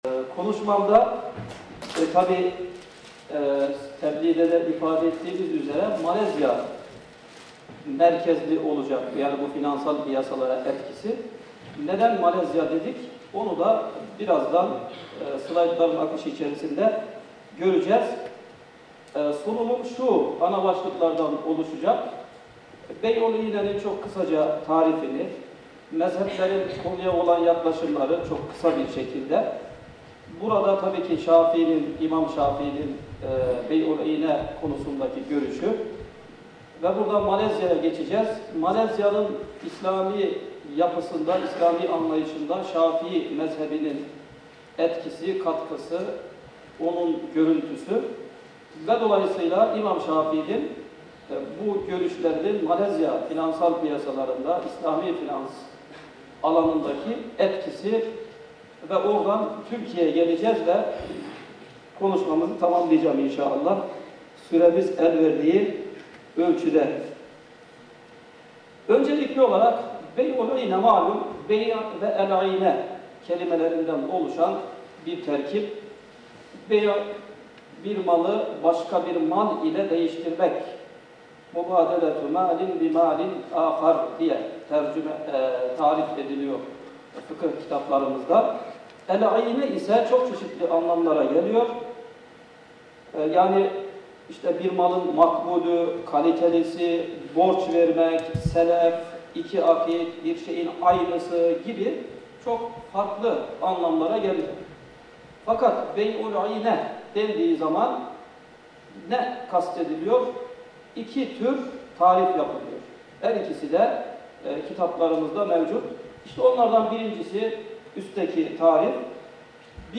İmam Şafii Konferansı